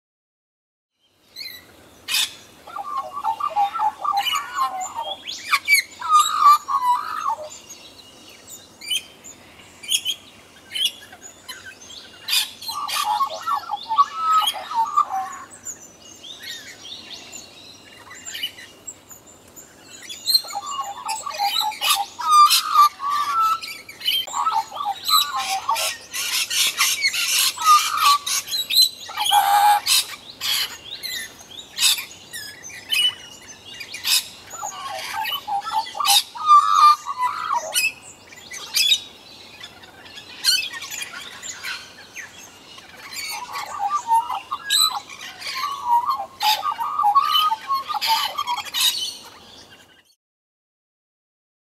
En hij zingt zo lief, wow! Zo maar, twee meter bij je vandaan gaat ie tegen je aan zitten tjoerelen.
HT Australian Magpie
australian_magpie_singing.mp3